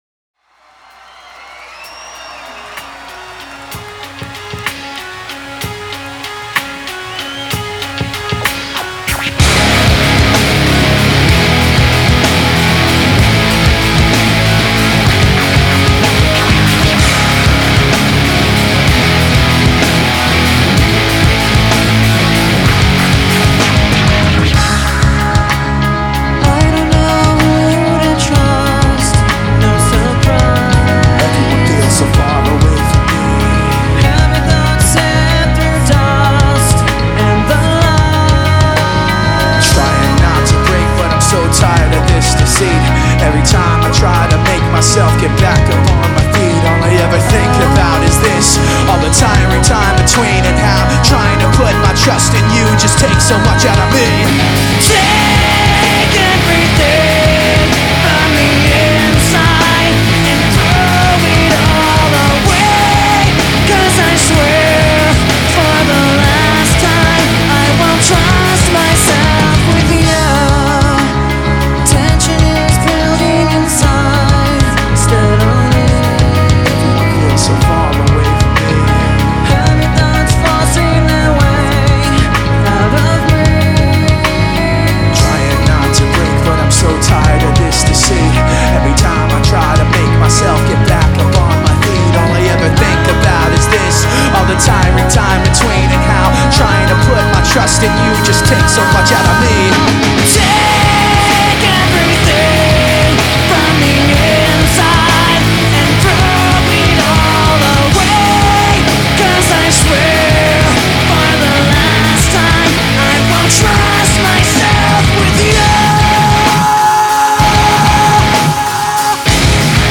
Live LPU Tour 2003